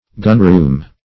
Search Result for " gunroom" : The Collaborative International Dictionary of English v.0.48: Gunroom \Gun"room`\ (g[u^]n"r[=oo]m`), n. (Naut.)
gunroom.mp3